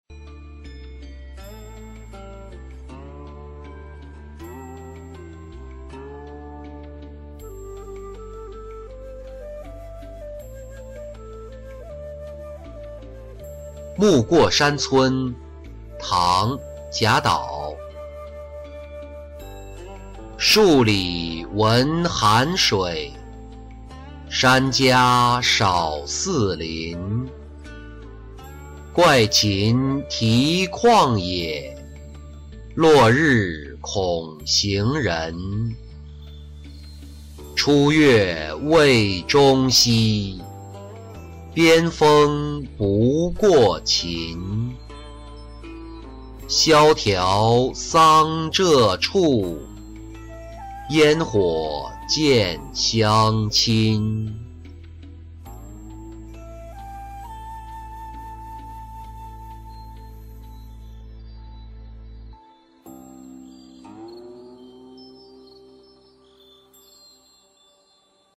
暮过山村-音频朗读